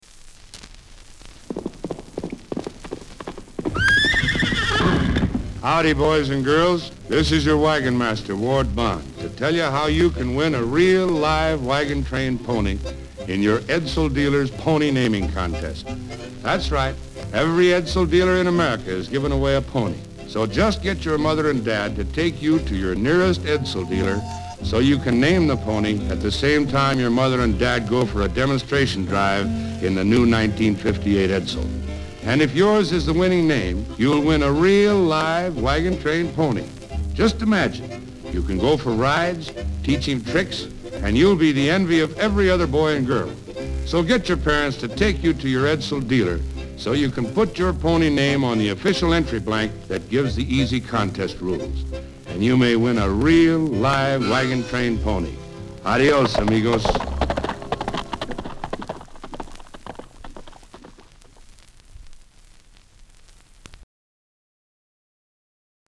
HERE to hear Ward Bond from "Wagon Train" in a radio ad for our Edsel Pony Contest.
PonyContest.mp3